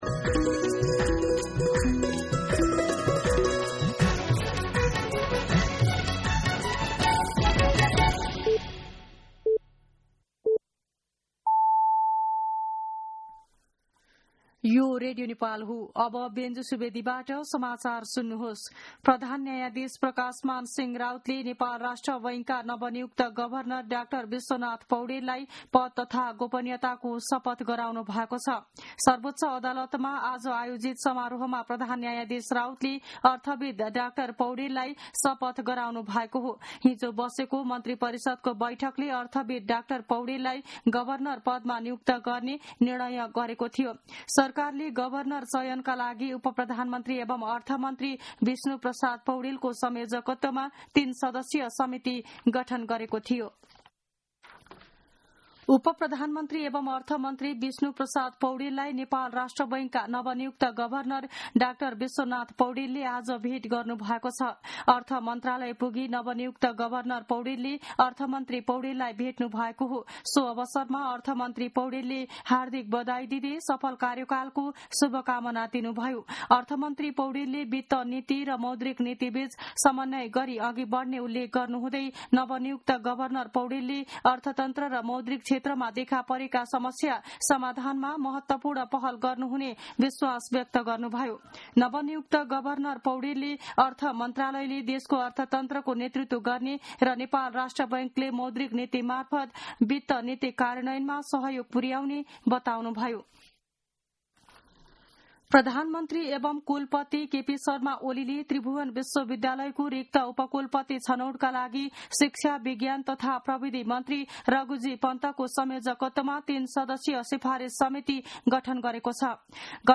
मध्यान्ह १२ बजेको नेपाली समाचार : ७ जेठ , २०८२